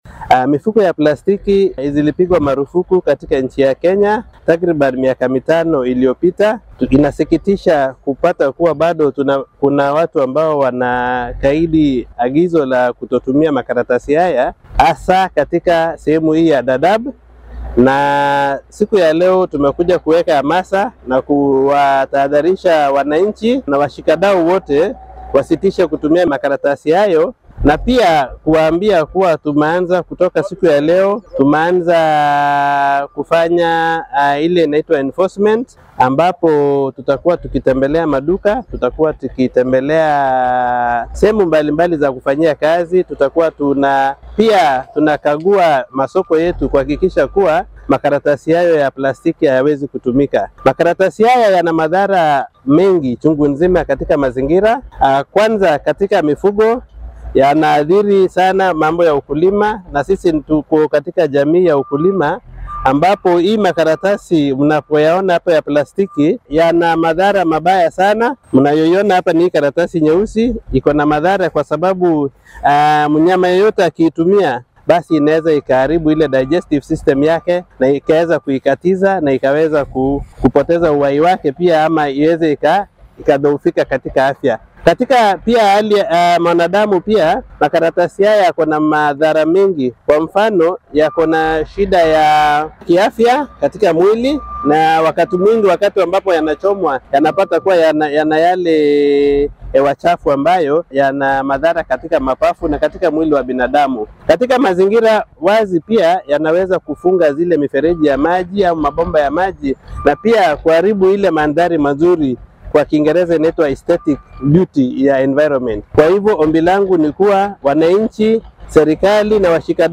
Waxaa looga digay isticmaalka bacaha oo dowladdu ay mamnuucday ku dhawaad shan sano ka hor balse wali qeybo badan oo ka mid ah xeryaha qaxootiga ah laga adeegsado. Madaxa maamulka qaran ee NDMA ismaamulka Garissa Issac Kimtai oo warbaahinta Star Kula hadlay deegaanka Dadaab ayaa sheegay in ay tahay danbi isticmaalka bacaha oo halis cafimaad ku ah dadka iyo duunyada sidoo kalena deeganka u daran sida uu hadalka u dhigay.